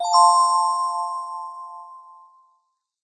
bright_bell_chime.ogg